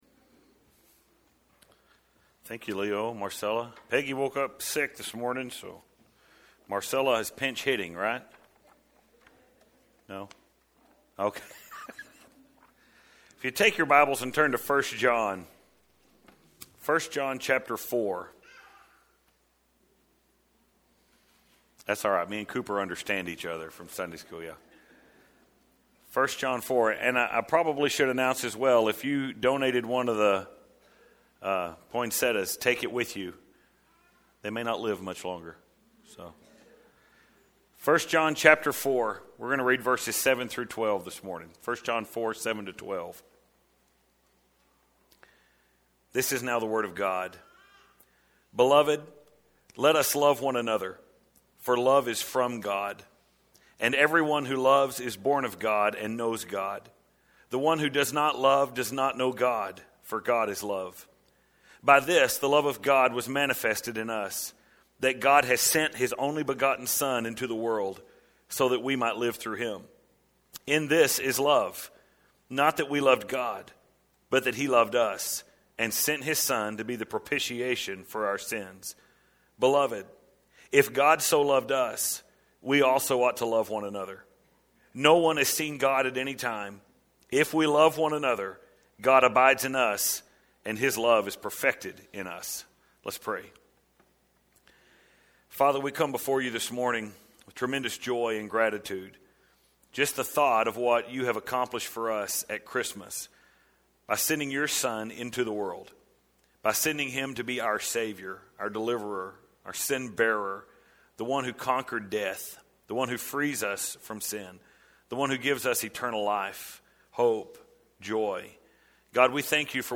But this morning in this special Christmas sermon We can sort of look at the unseen motive behind all of that. What is it that caused a holy God to send His Son to cleanse sinners?